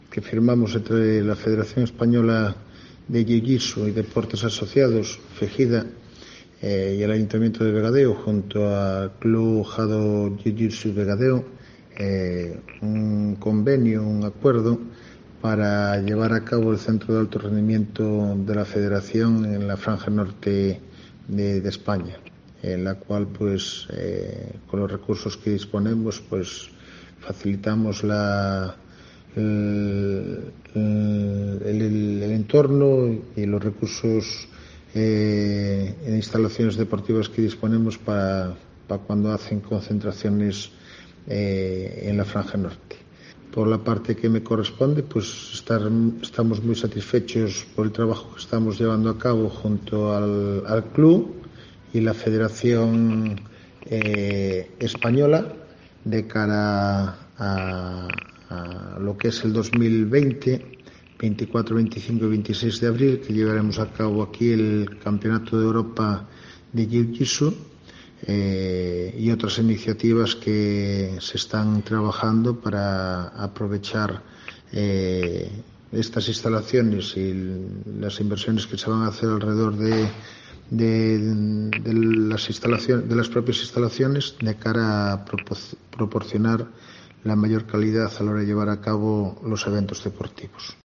Declaraciones del Alcalde de Vegadeo